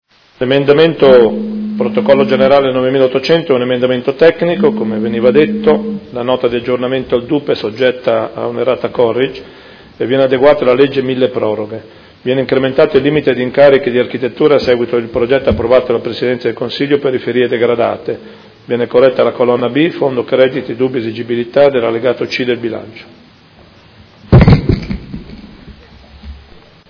Seduta del 26 gennaio. Bilancio preventivo: emendamento n°9800